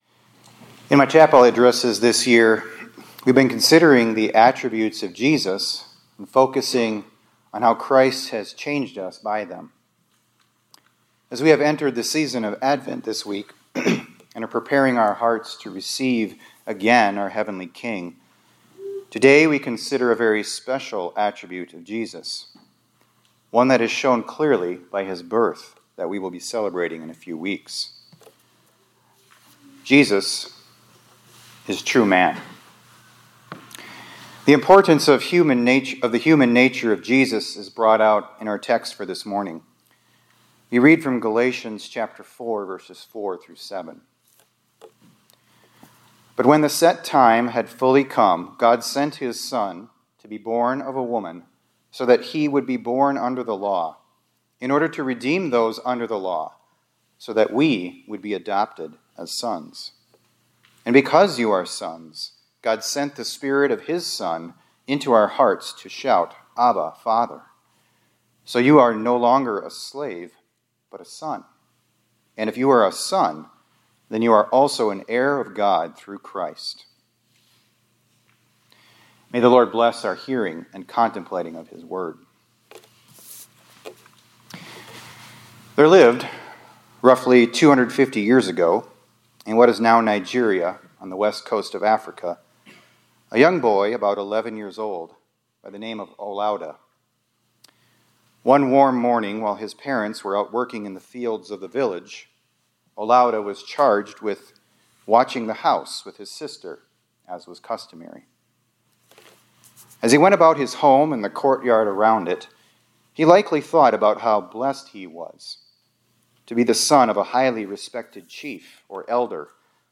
2024-12-06 ILC Chapel — Jesus Is True Man